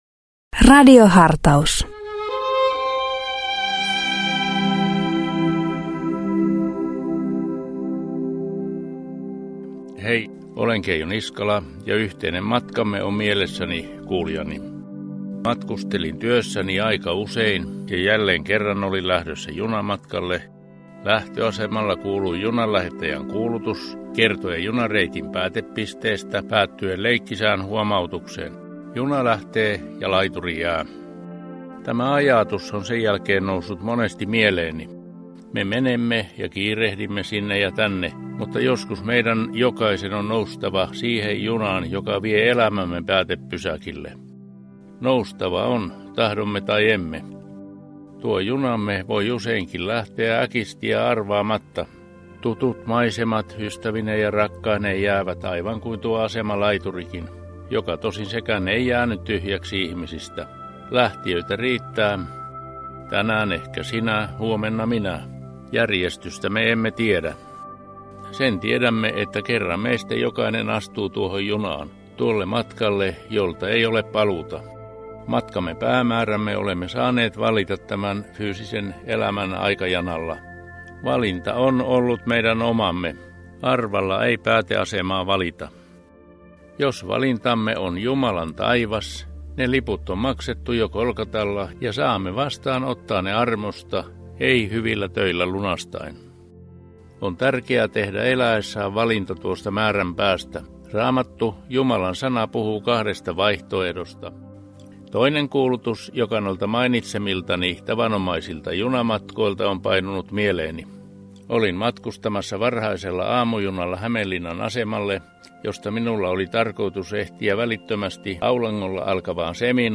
PostRadio Dei lähettää FM-taajuuksillaan radiohartauden joka arkiaamu kello 7.50. Radiohartaus kuullaan uusintana iltapäivällä kello 17.05.
Pääpaino on luterilaisessa kirkossa, mutta myös muita maamme kristillisen perinteen edustajia kuullaan hartauspuhujina.